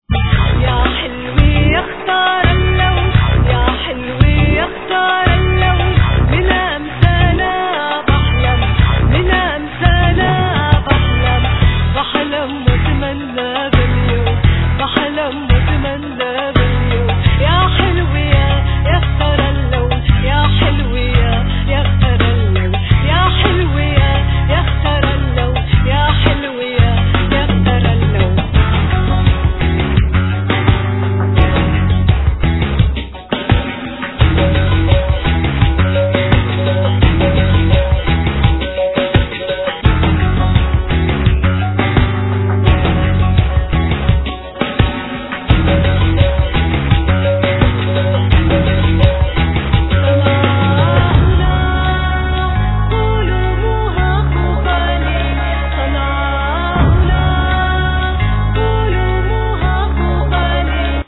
Vocal
Arrangements, Samples, Keys, Guitar, Bass, Saz, Percussions
Drums
Tabla, Disgeridoo, Percussions, Aliquot singing